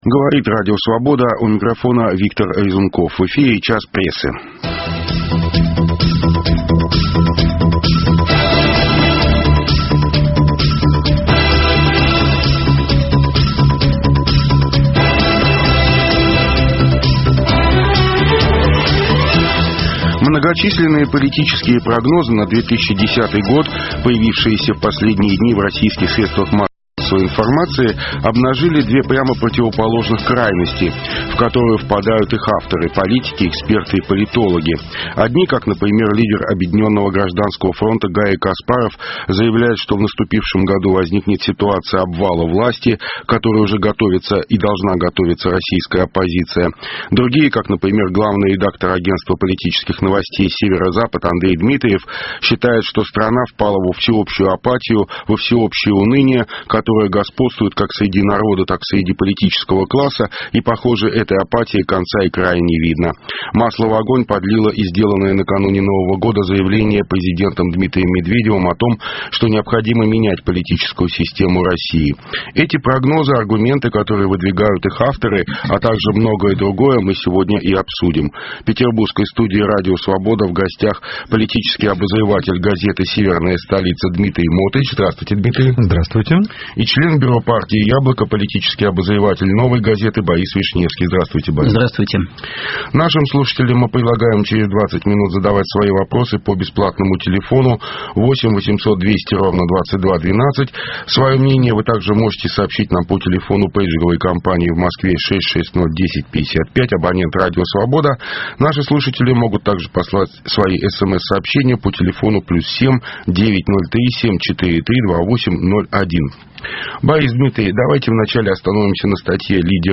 Утром в газете, а с часу дня в прямом эфире - обсуждение самых заметных публикации российской и зарубежной печати. Их авторы и герои - вместе со слушателями.